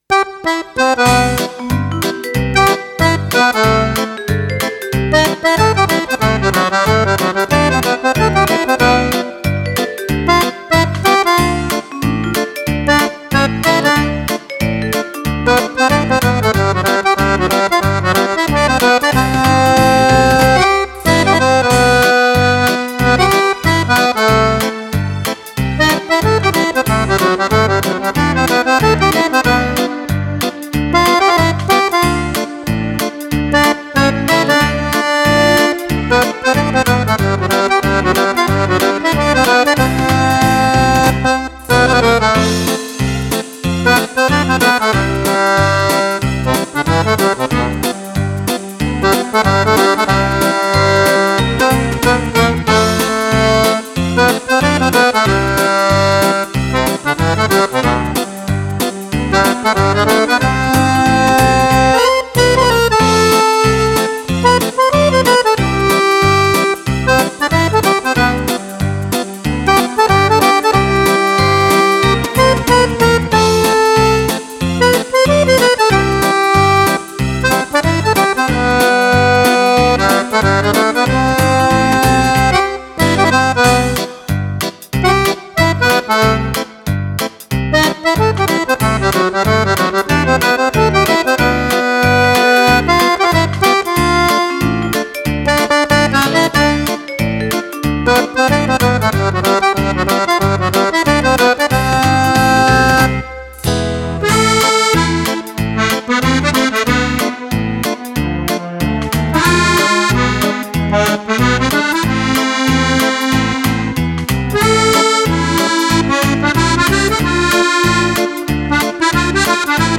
18 BALLABILI PER FISARMONICA
CHITARRE